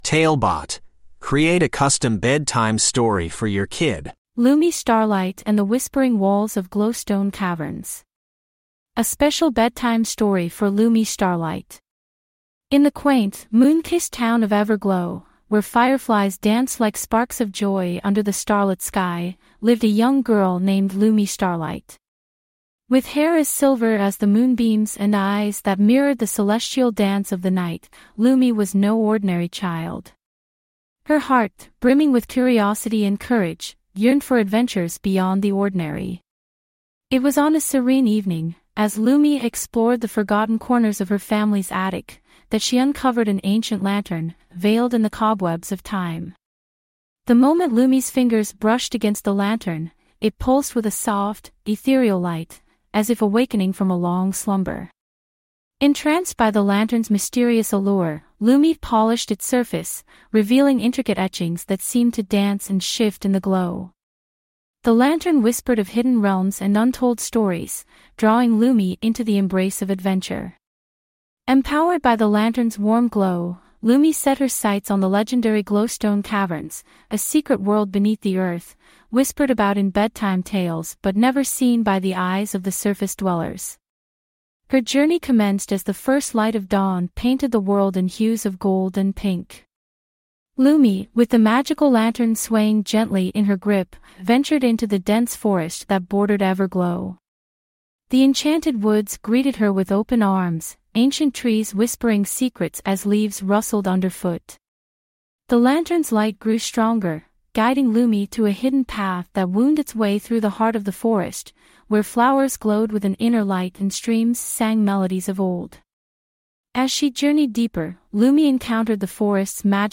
TaleBot AI Storyteller